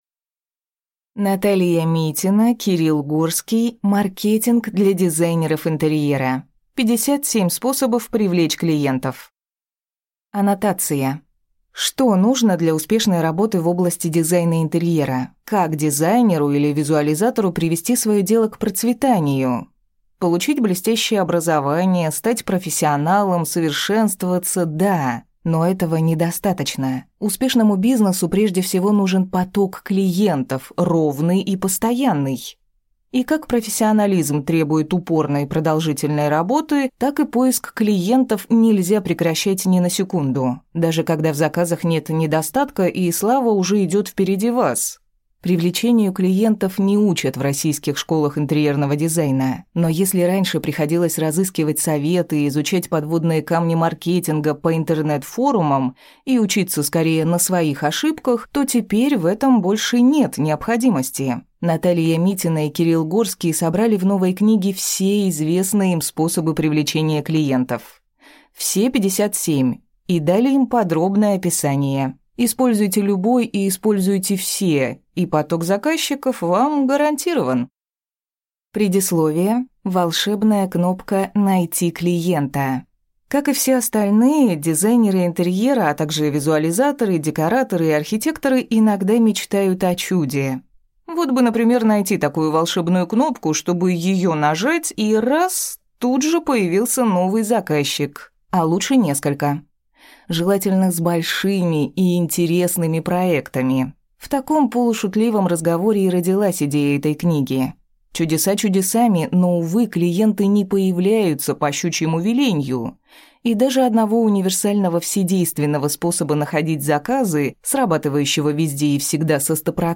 Аудиокнига Маркетинг для дизайнеров интерьера. 57 способов привлечь клиентов | Библиотека аудиокниг